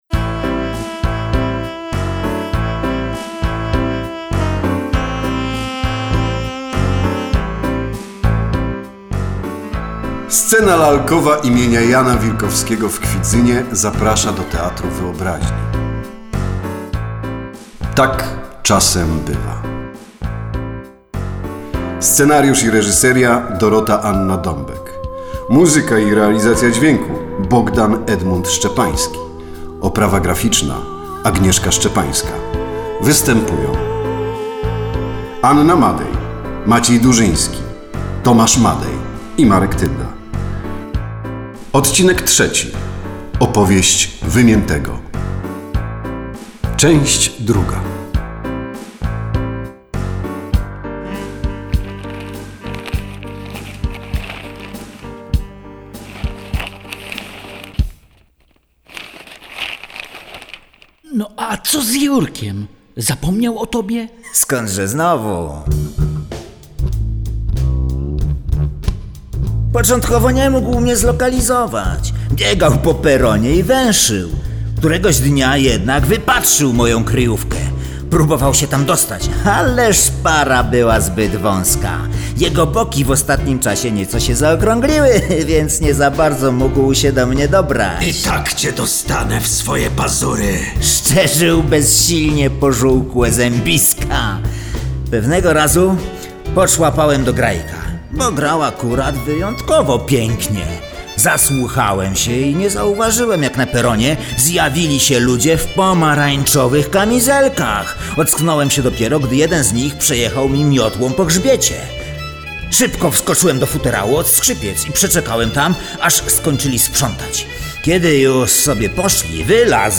Tak czasem bywa – serial audio – Scena Lalkowa imienia Jana Wilkowskiego w Kwidzynie
Każdy odcinek słuchowiska dedykowany jest innej postaci. Pierwszy to historia Nowego, drugi skupia się na Pięknisiu, trzeci dedykowany jest Wymiętemu.